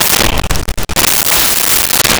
Whip Crack 01
Whip Crack 01.wav